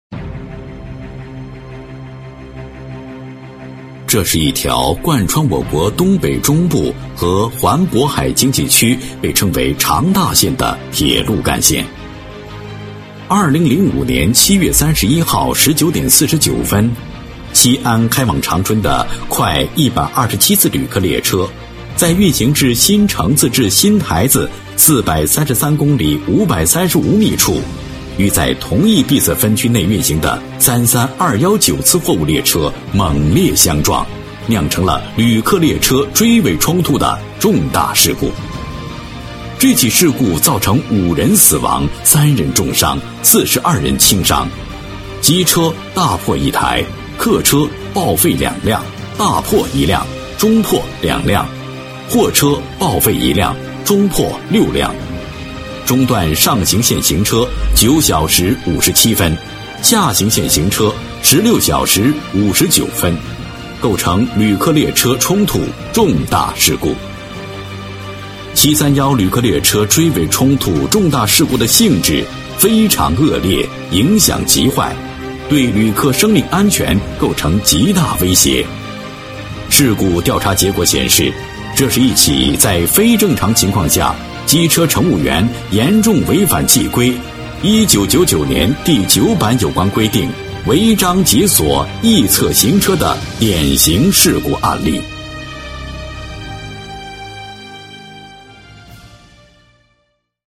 男138号-企业宣传片配音-大气沉稳-河南万江新能源开发有限公司